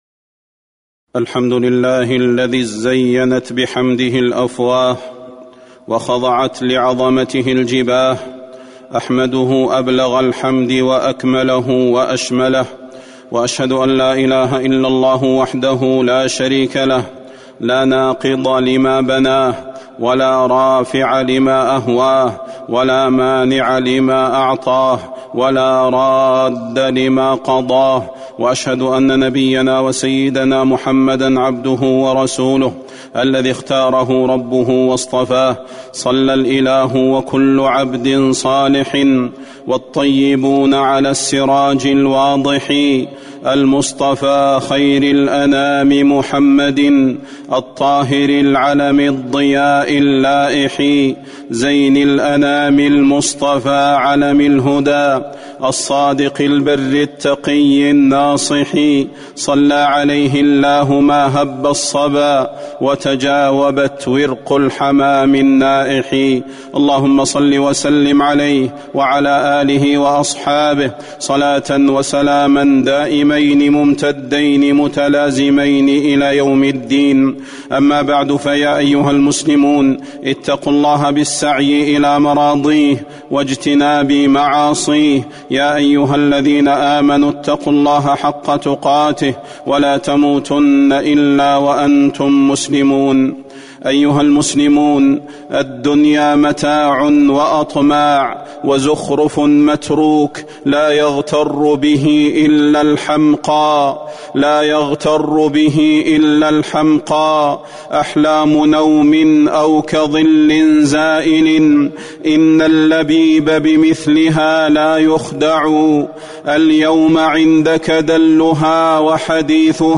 تاريخ النشر ١٩ محرم ١٤٤٣ هـ المكان: المسجد النبوي الشيخ: فضيلة الشيخ د. صلاح بن محمد البدير فضيلة الشيخ د. صلاح بن محمد البدير الوصية بالعمل قبل حلول الأجل